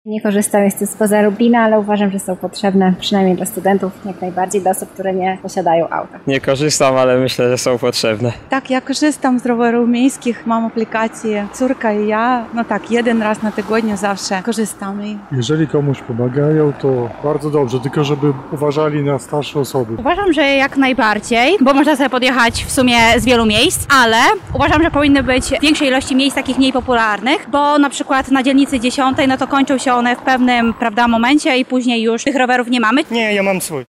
[SONDA] Co Lublinianie sądzą o rowerze miejskim?
Zapytaliśmy mieszkańców Lublina, czy korzystają z tego rozwiązania i co o nim sądzą:
sonda